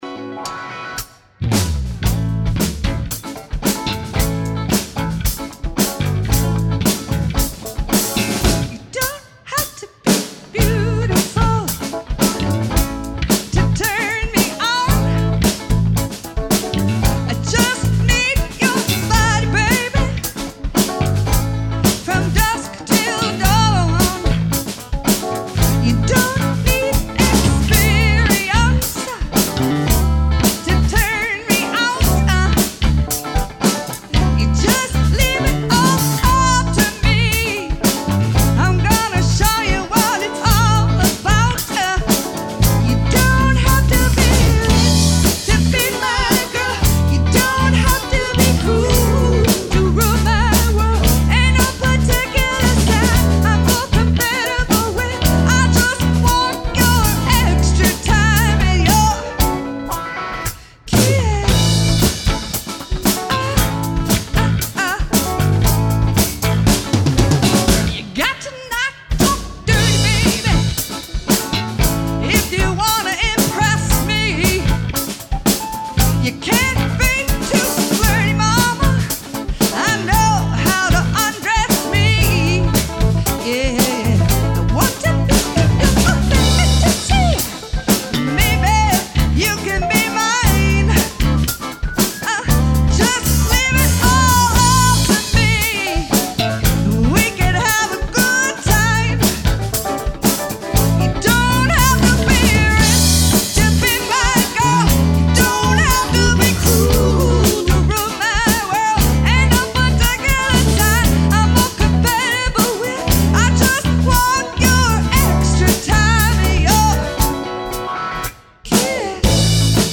Here are a few live clips from some of our recent shows.